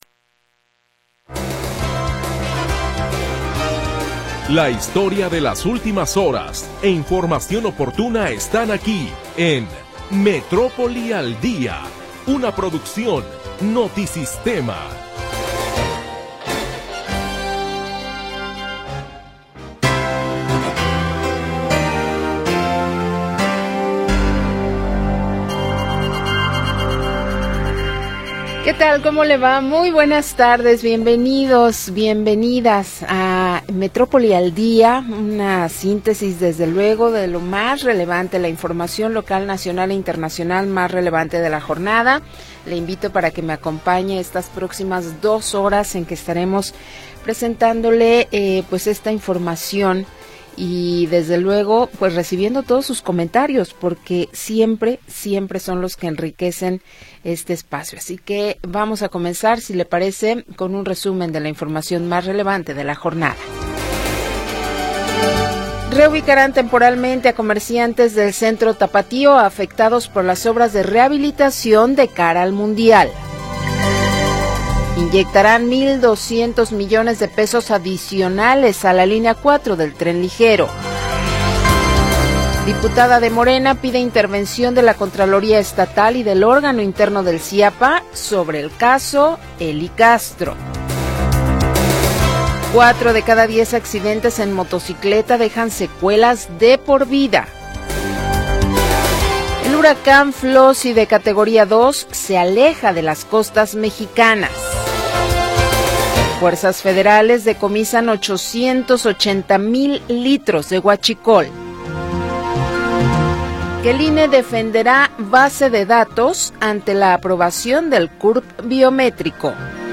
Primera hora del programa transmitido el 2 de Julio de 2025.